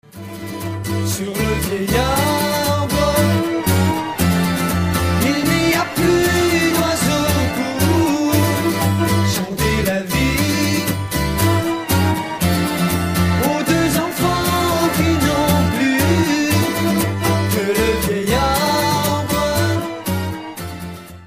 guitares
basse, vibraphone
piano
flûte